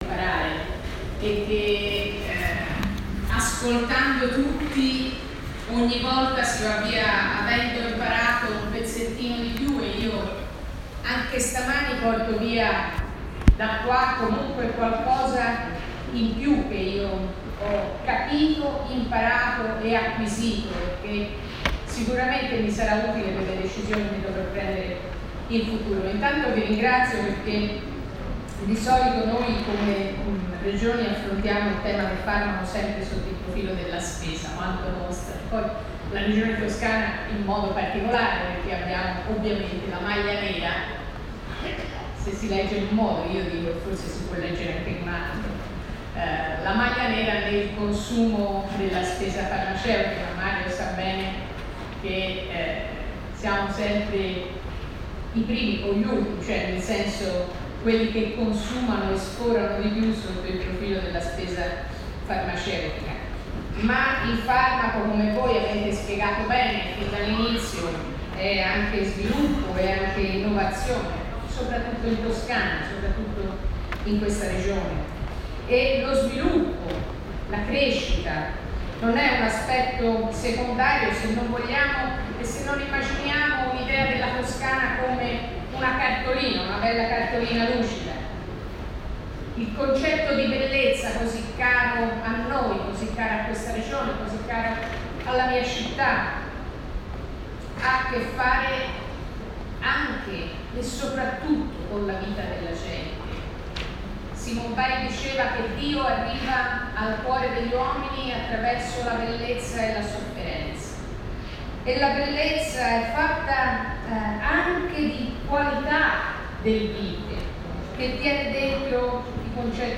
Industria farmaceutica e sanità in Toscana. la parola all'assessore Sefania Saccardi